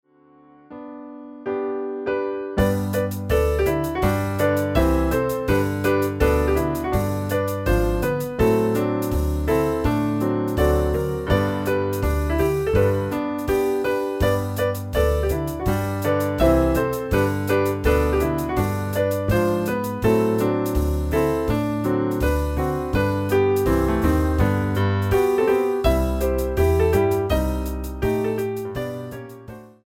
MIDI arrangement for Piano, Bass and Drums
Piano Channel 1
Bass Channel 5
Drums Channel 10